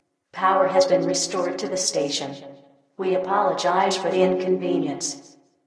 * Mix stereo announcements to mono
power_on.ogg